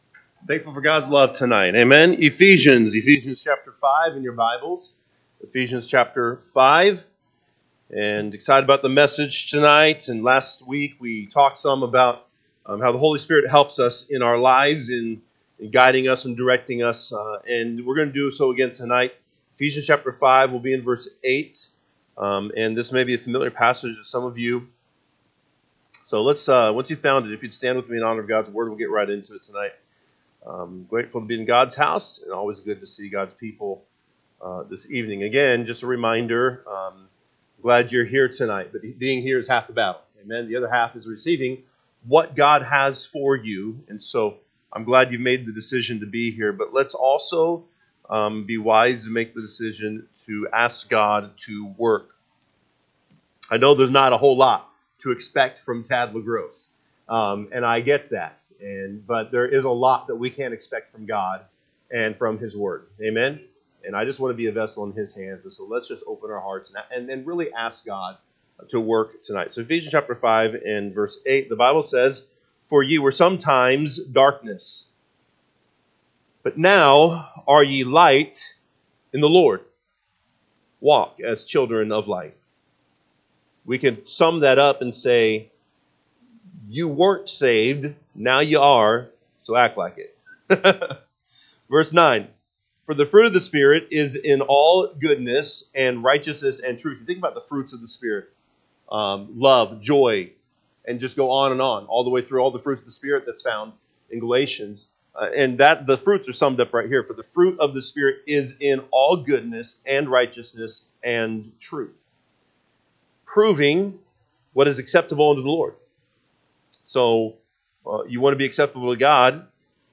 Wednesday Evening